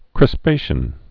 (krĭs-pāshən)